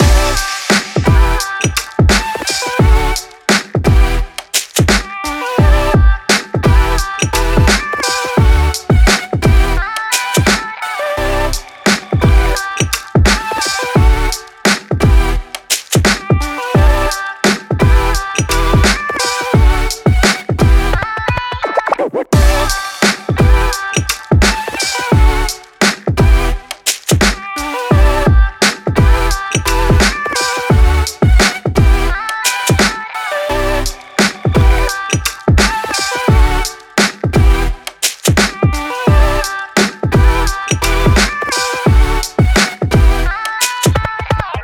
BPM86、Em/Gmaj、同じドラムセット縛りの6パターンのビート・トラックです。
EMO HIPHOP LOOP TRACK BPM86 Em/Gmaj pattern E